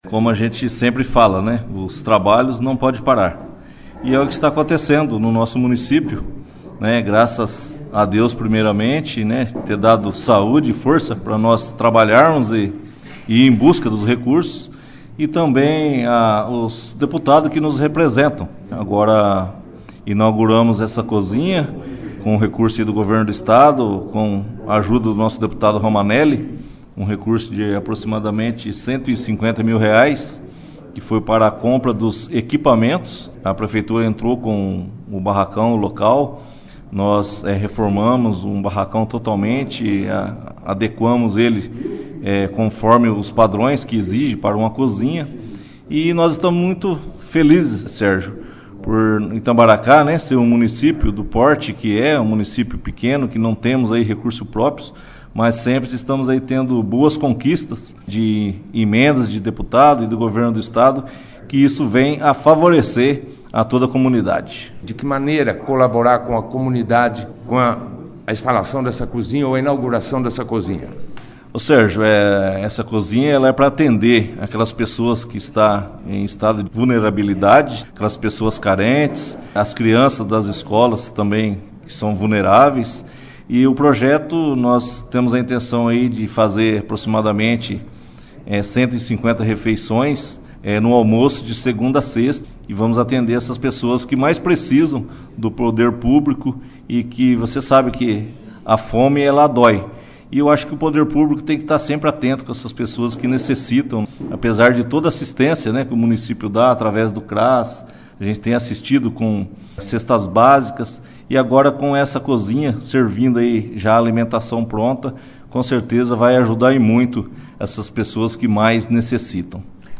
A presença do deputado foi destaque da 2ª edição do jornal Operação Cidade desta sexta-feira, 14/08, com a participação do prefeito de Itambaracá, Carlinhos e o Deputado.